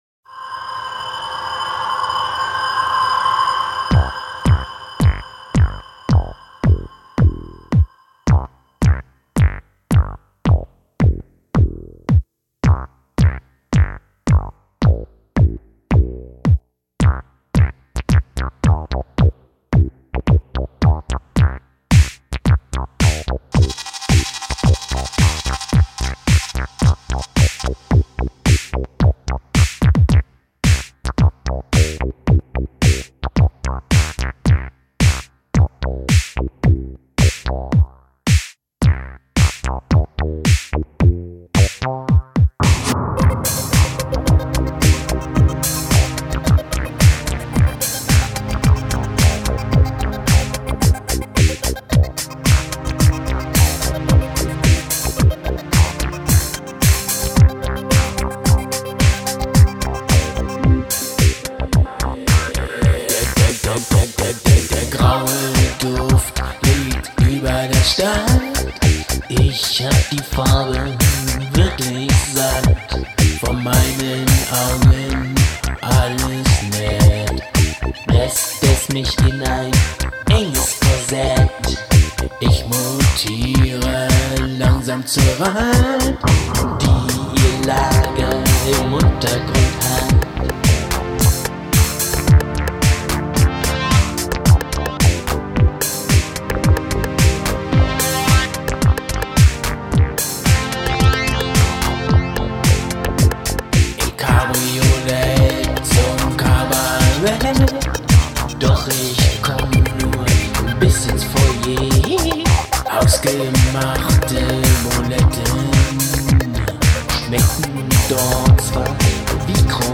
Die Krokette unter den Tanzdielenkrachern.
Synthesizer